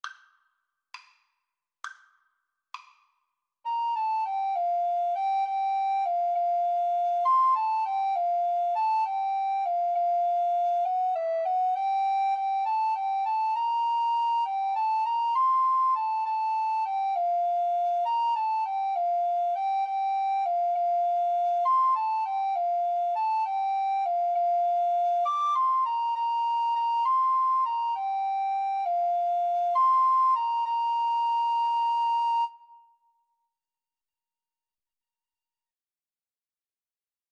6/8 (View more 6/8 Music)
Classical (View more Classical Alto Recorder Duet Music)